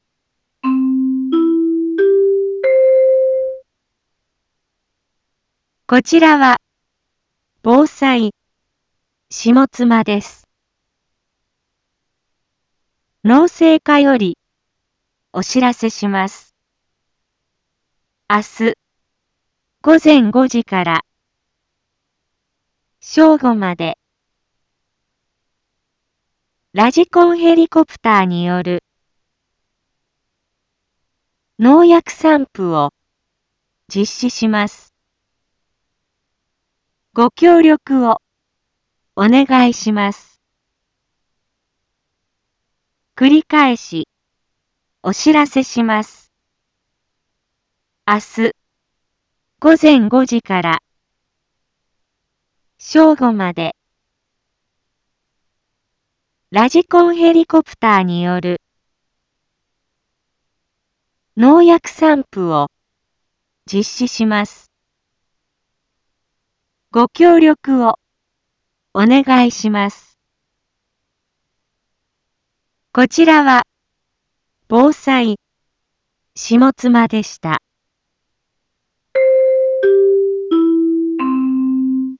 一般放送情報
Back Home 一般放送情報 音声放送 再生 一般放送情報 登録日時：2022-04-29 12:31:26 タイトル：麦のﾗｼﾞｺﾝﾍﾘによる防除（上妻） インフォメーション：こちらは防災下妻です。